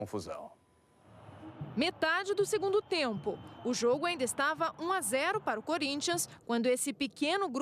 Note : When I played attached aac file in VLC the quality is good and does not have any glitch.